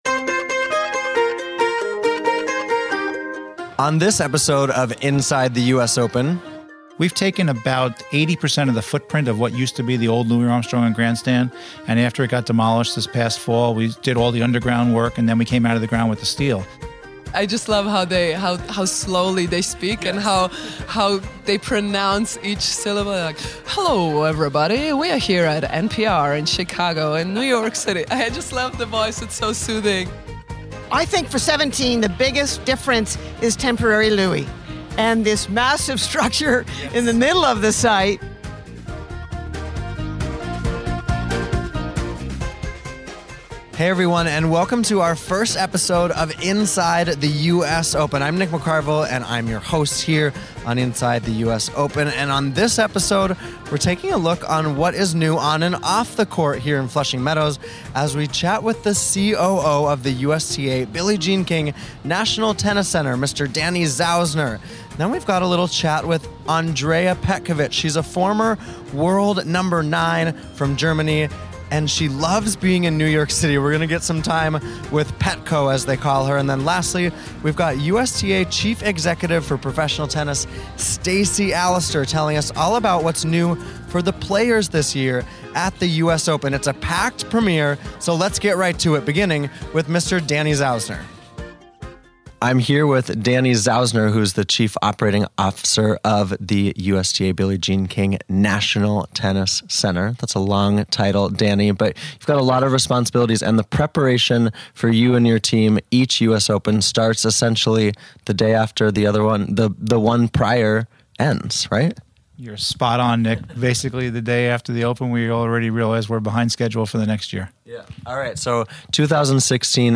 We're talking what's new on and off the court at the 2017 US Open in our first episode and we also chat New York culture with Andrea Petkovic, who shows off her NPR voicing skills.